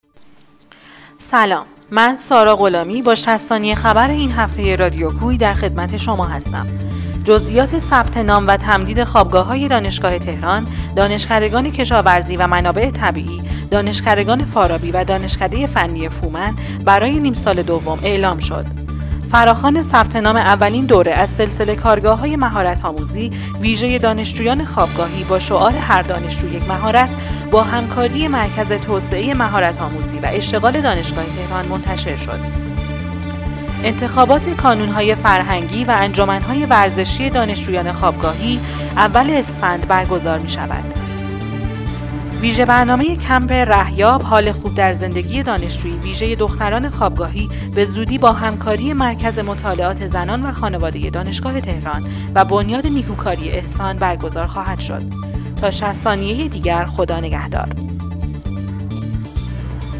اخبار ۶۰ ثانیه‌ای [۱۲ بهمن ۱۴۰۱]